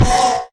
Minecraft Version Minecraft Version 1.21.5 Latest Release | Latest Snapshot 1.21.5 / assets / minecraft / sounds / mob / horse / skeleton / hit1.ogg Compare With Compare With Latest Release | Latest Snapshot